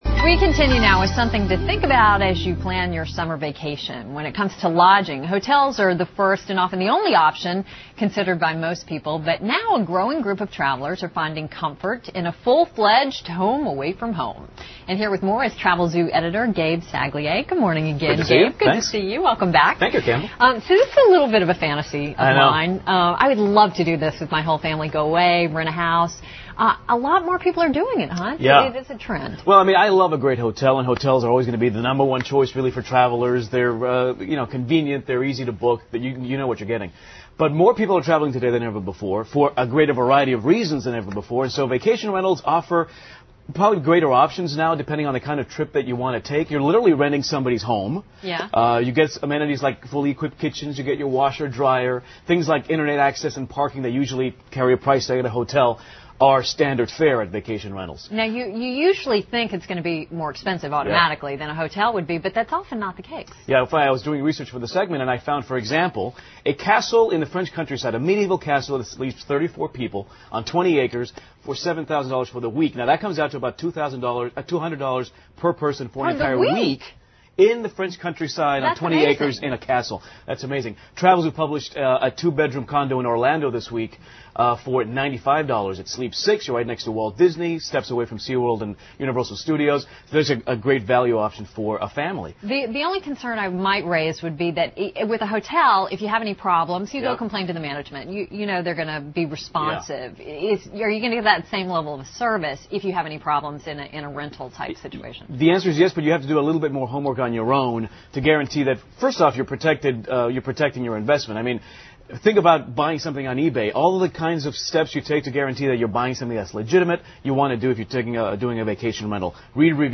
访谈录 Interview 2007-04-30&05-02, 欢乐度假 听力文件下载—在线英语听力室